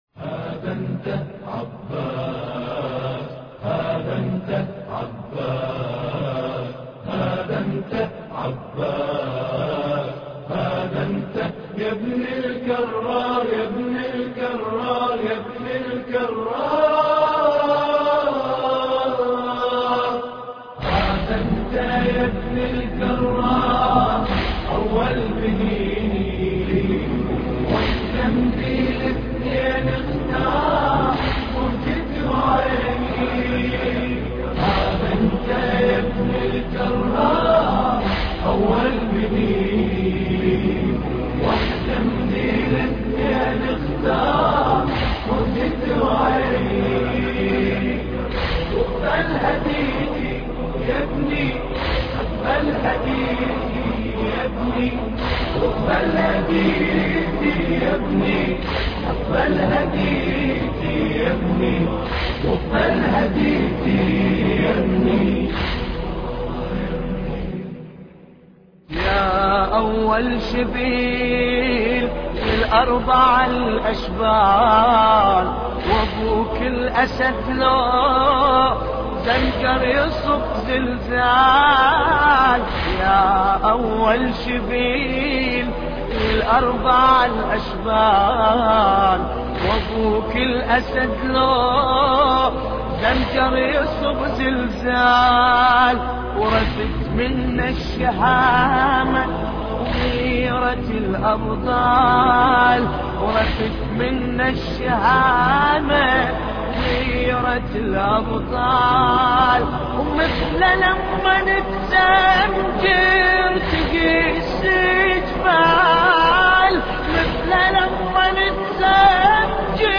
مراثي أبو الفضل العباس (ع)